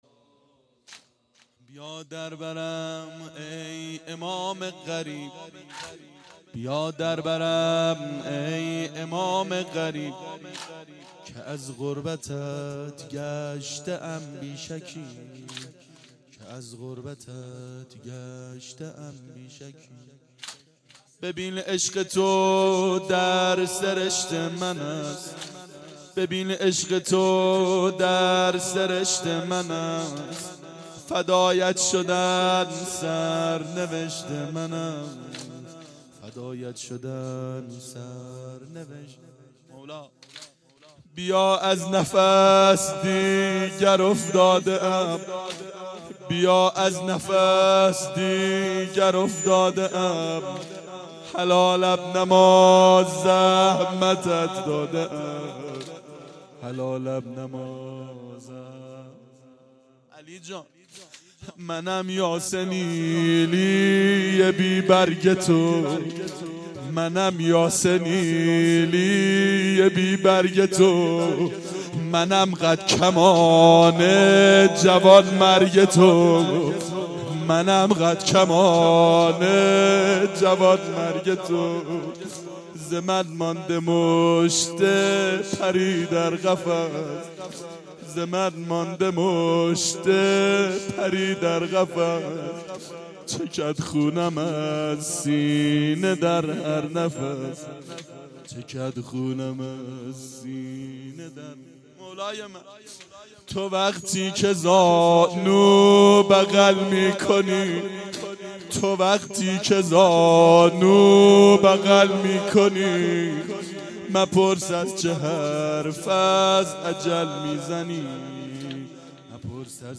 • فاطمیه